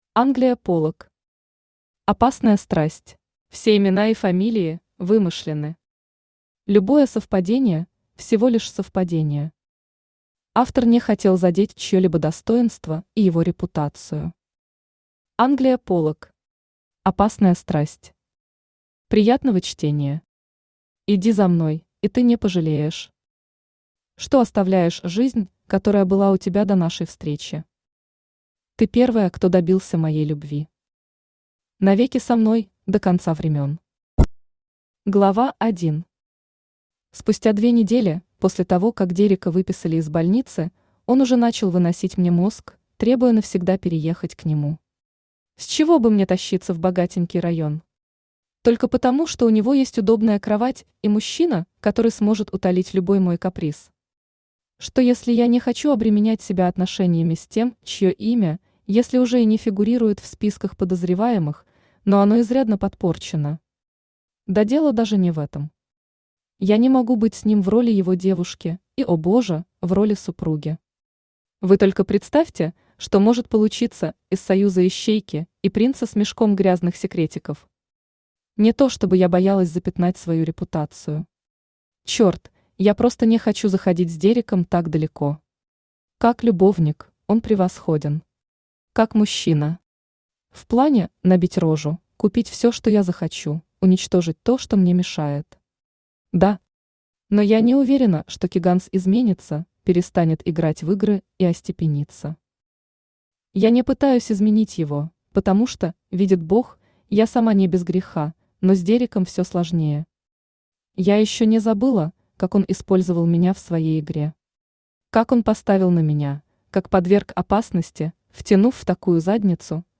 Аудиокнига Опасная Страсть | Библиотека аудиокниг
Aудиокнига Опасная Страсть Автор Англия Полак Читает аудиокнигу Авточтец ЛитРес.